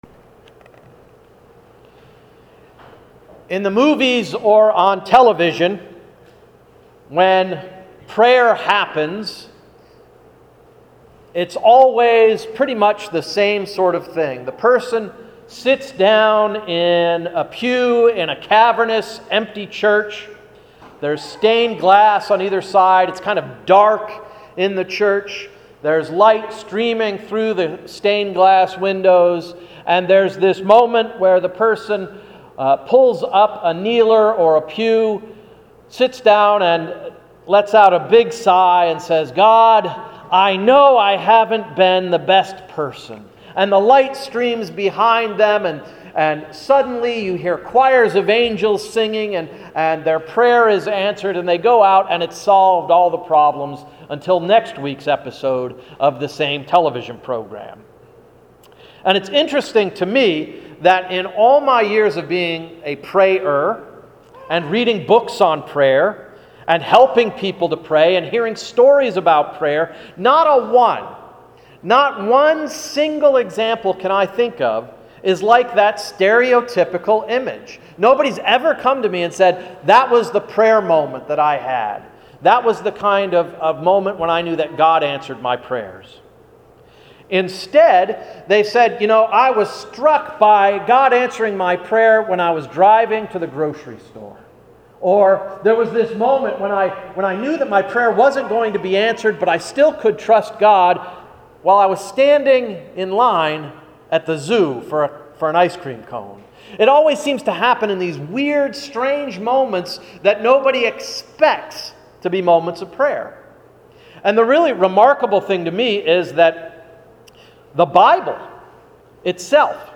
Sermon of July 28–“The Tough Conversations”